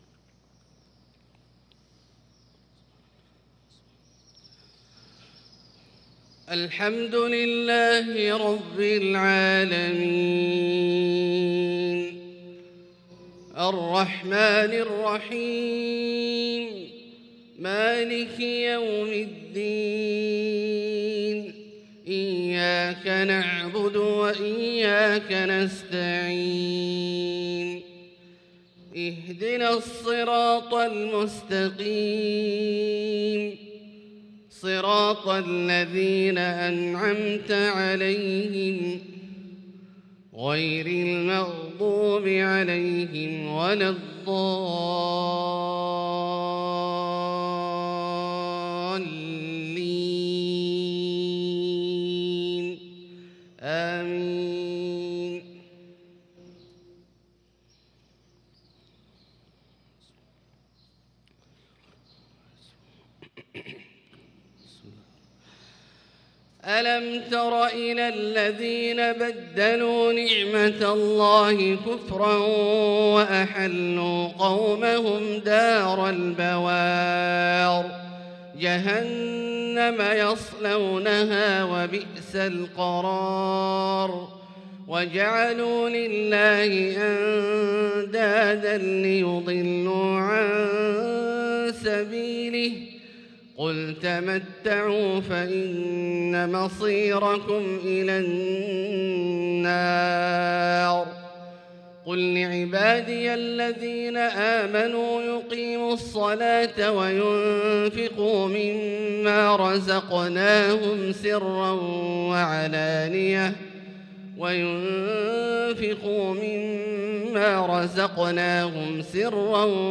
صلاة الفجر للقارئ عبدالباري الثبيتي 4 شعبان 1445 هـ
تِلَاوَات الْحَرَمَيْن .